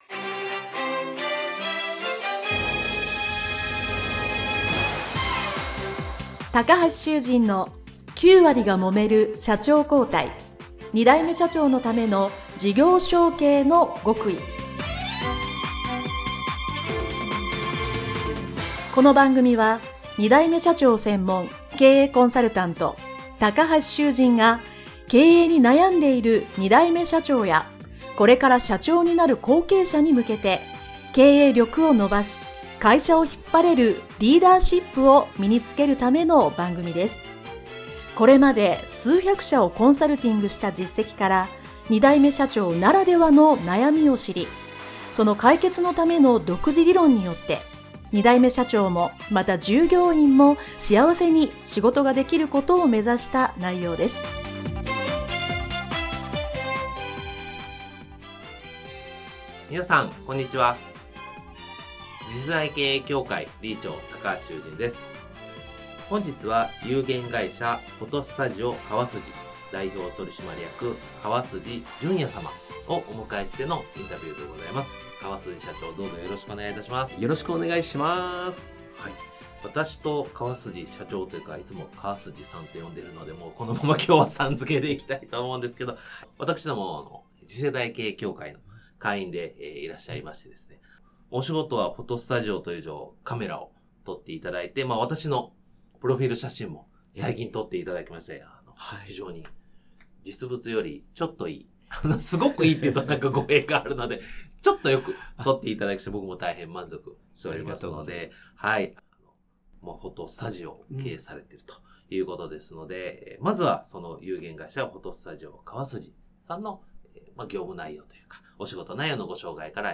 インタビュー前編